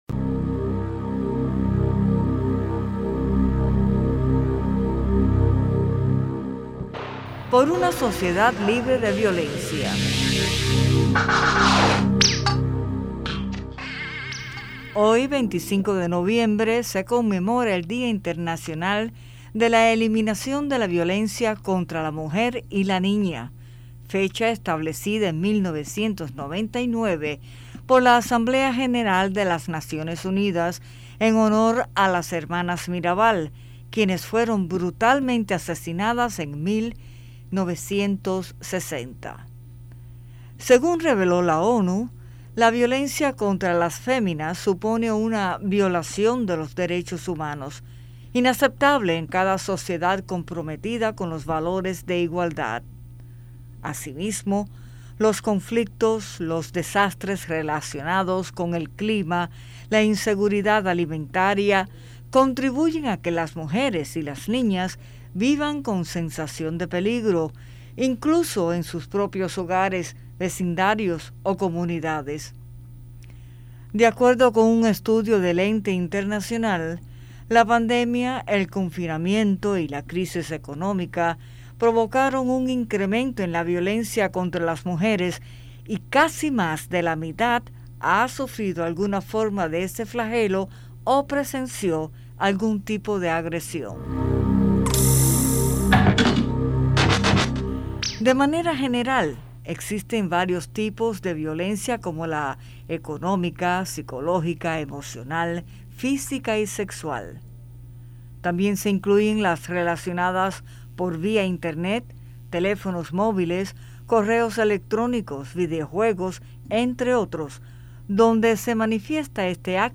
desde La Habana